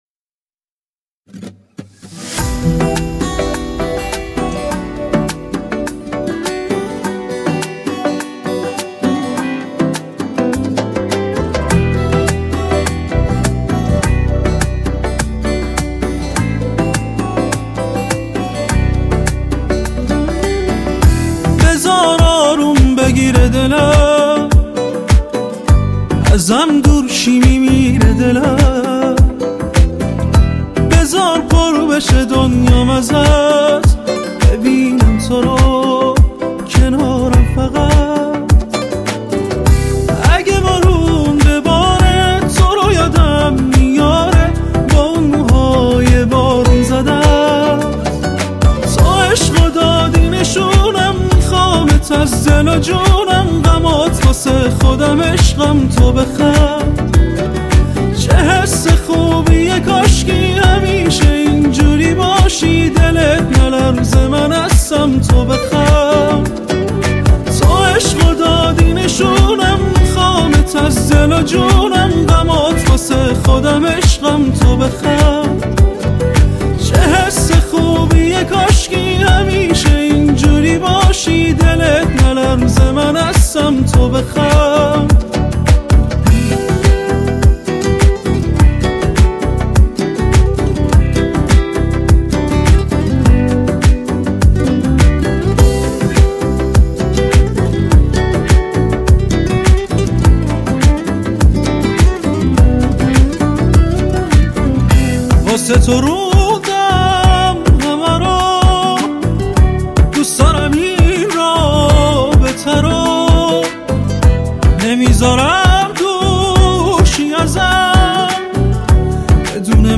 صداش و دوس دارم مخملی و آرامش بخشه😊